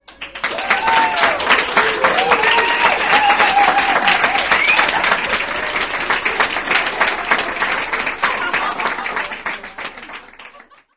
Applause Crowd Cheering sound effect ringtone free download
Sound Effects